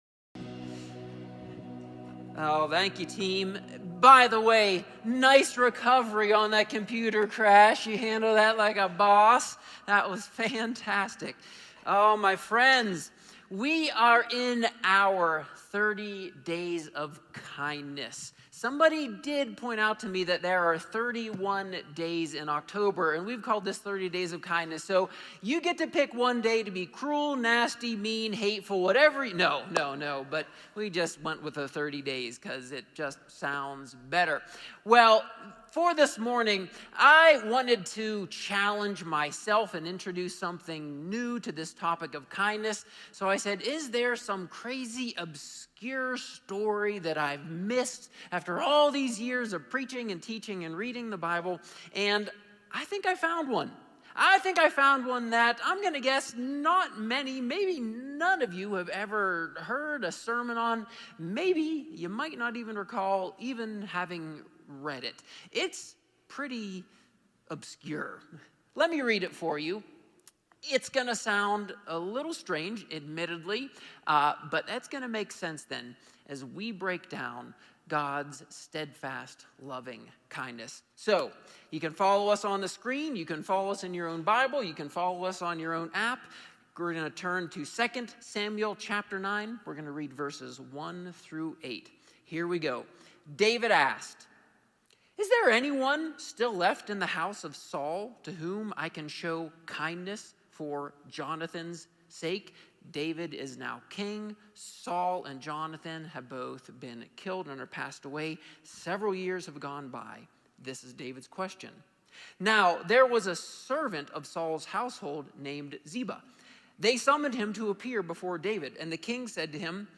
A sermon from the series "Bear Fruit."